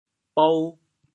bou2.mp3